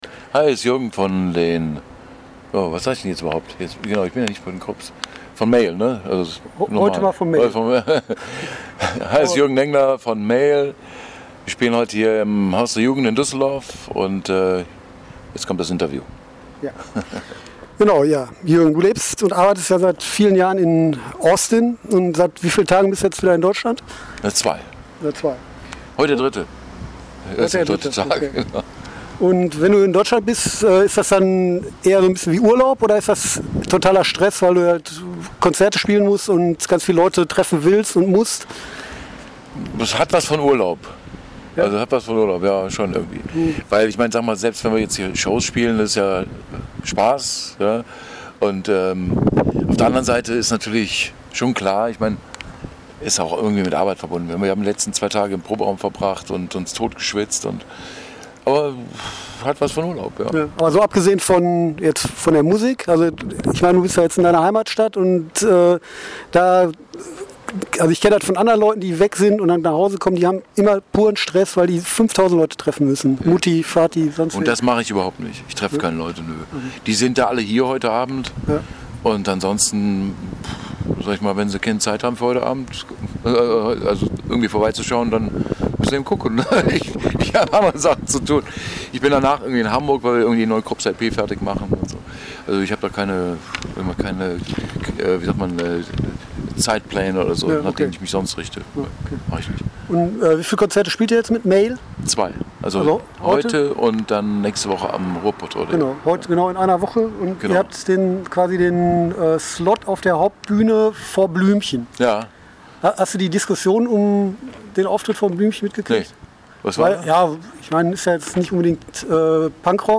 Bei schönen Wetter saßen wir vor dem "Haus der Jugend" und sprachen eine Dreiv...